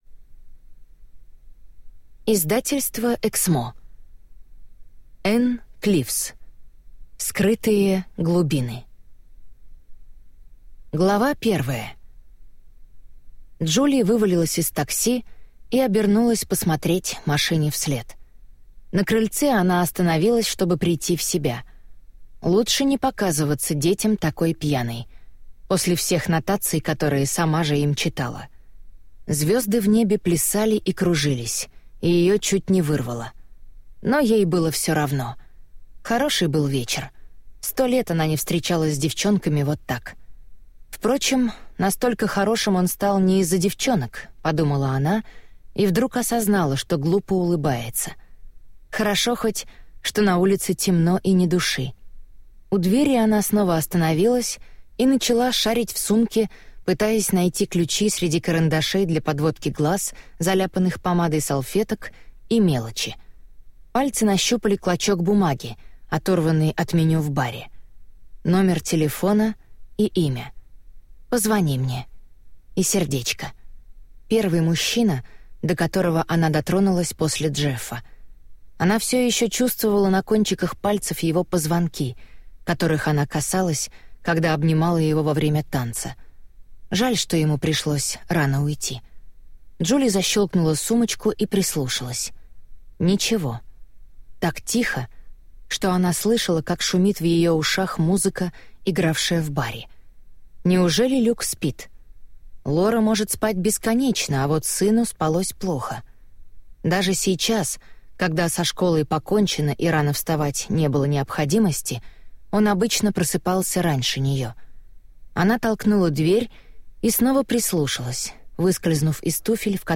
Аудиокнига Скрытые глубины | Библиотека аудиокниг